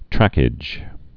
(trăkĭj)